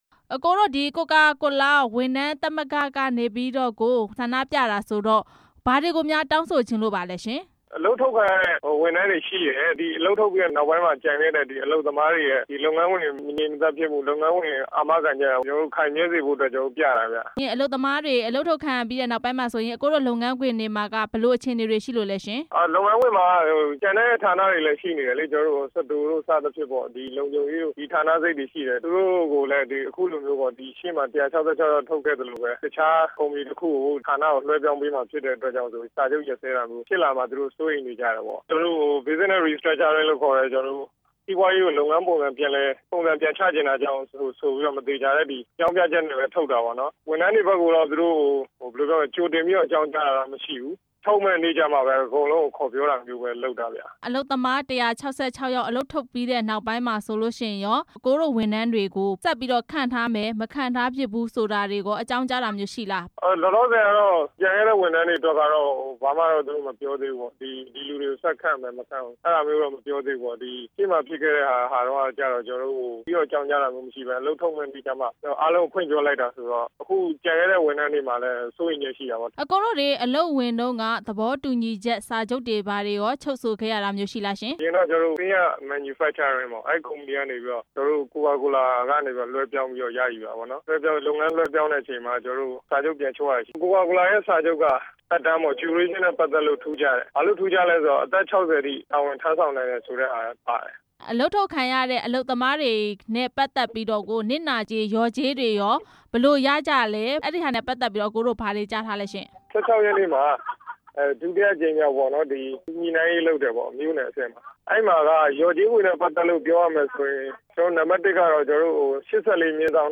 ကိုကာကိုလာ အလုပ်သမားတွေ ဆန္ဒပြရတဲ့အကြောင်း မေးမြန်းချက်